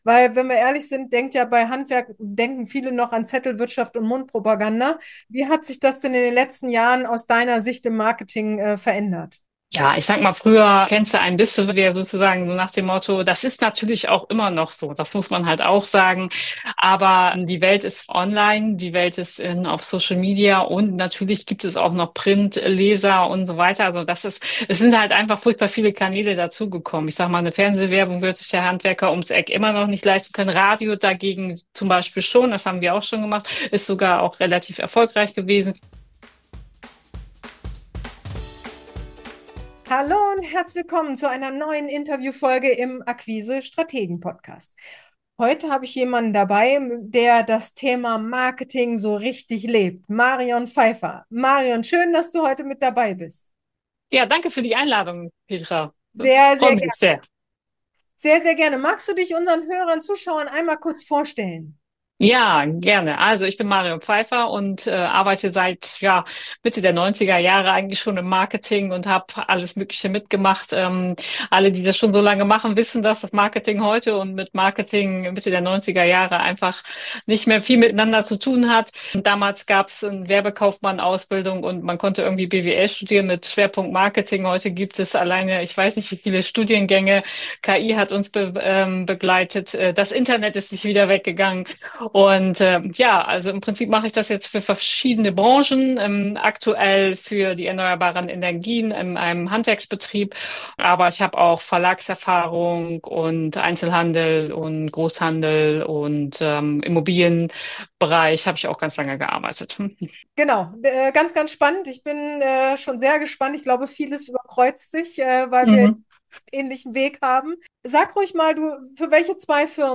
Wir sprechen über: die größten Denkfehler im Handwerksmarketing, wie du dich endlich vor die Kamera traust und warum Authentizität mehr bringt als Perfektion. Ein ehrliches Gespräch über Sichtbarkeit, Mut und modernes Handwerksmarketing.